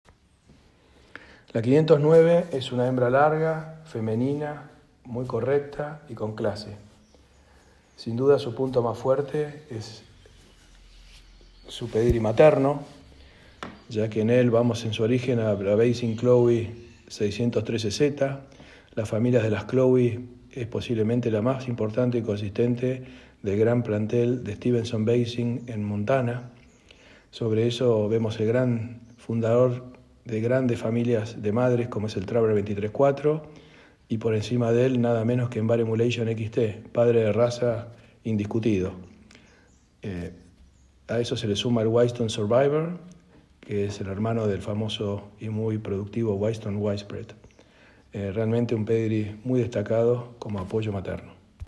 Comentario de nuestro genetista